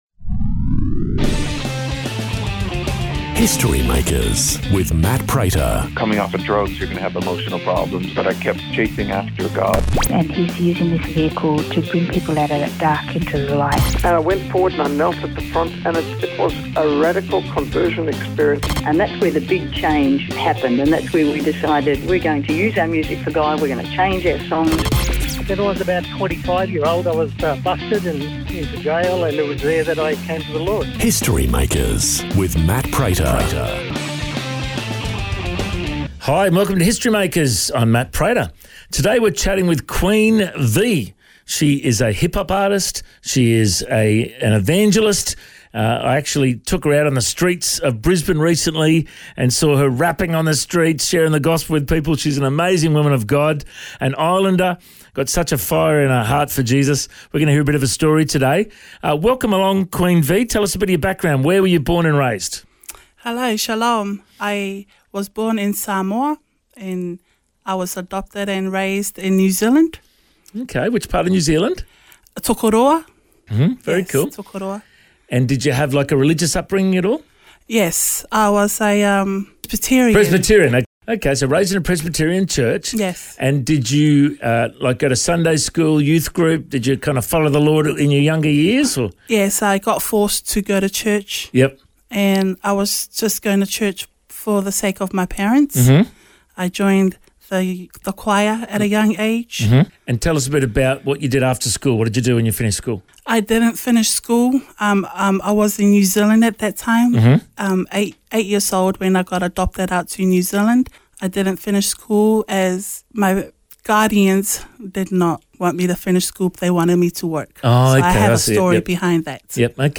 Interview, Music, Testimony